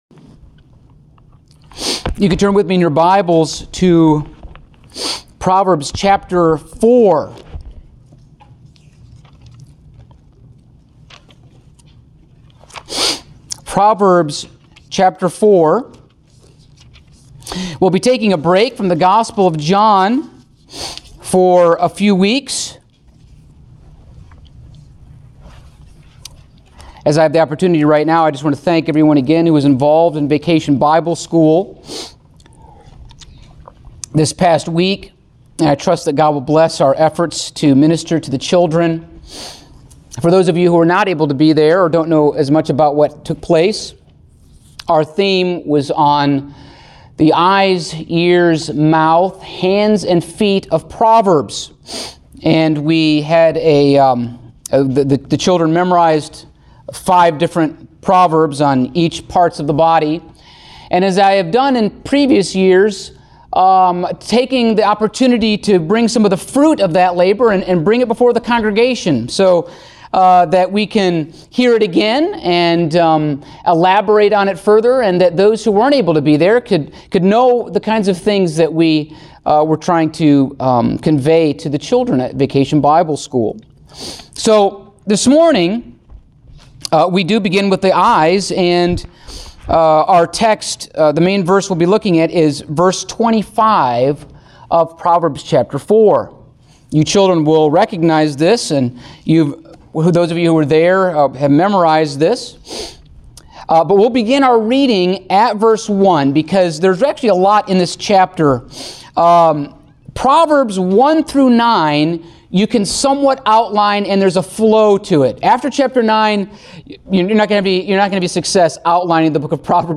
Passage: Proverbs 4:25 Service Type: Sunday Morning